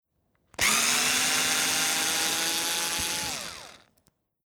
Akkuschrauber IXO 6
Schraube herausdrehen
58958_Schraube_herausdrehen.mp3